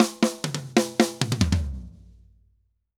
Drum_Break 136-4.wav